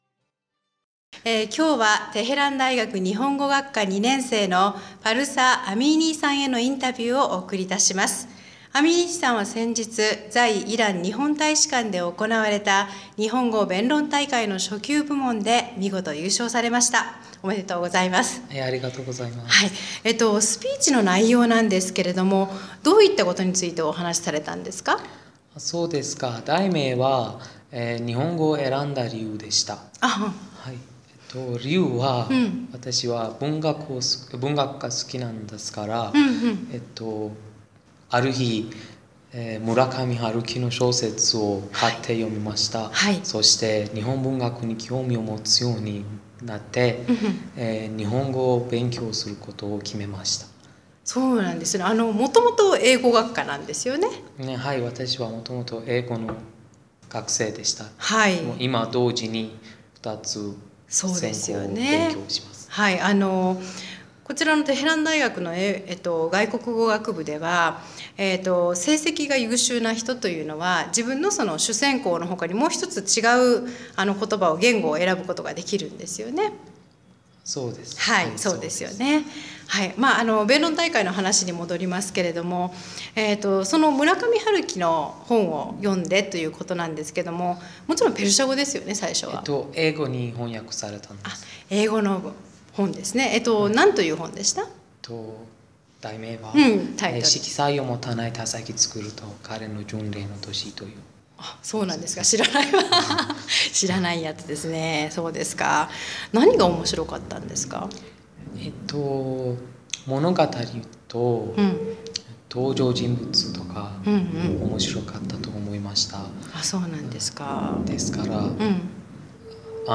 インタビュー